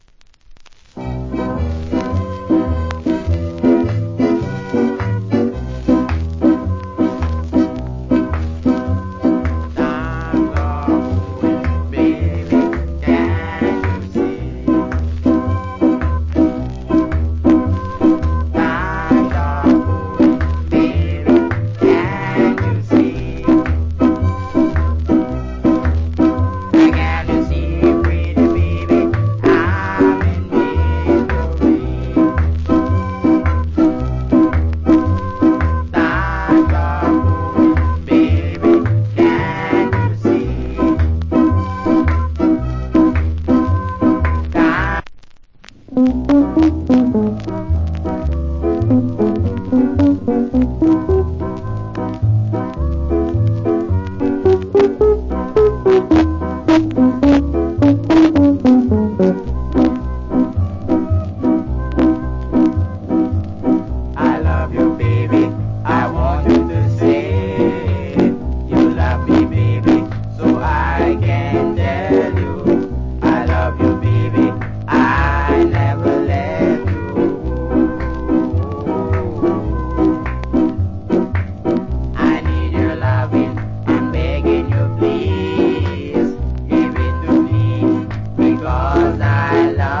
Early 60's Good JA R&B Vocal.